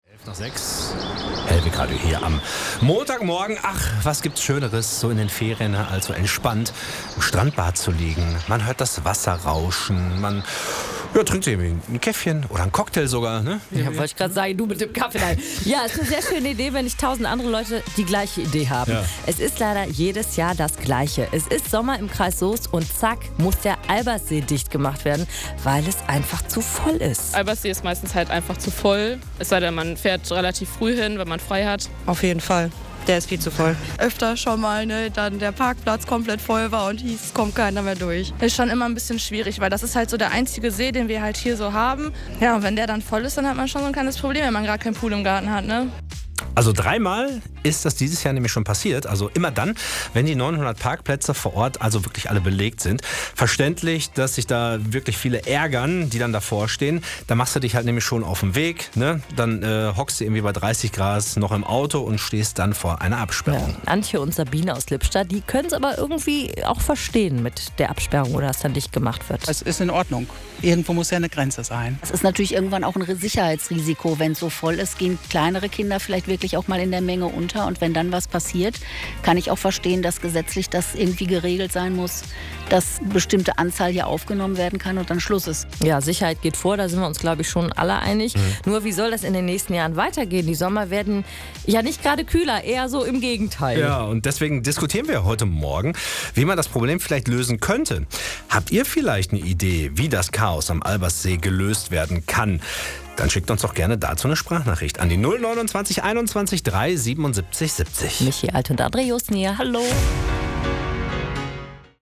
Reaktionen unserer Hellweg Radio-Hörer*innen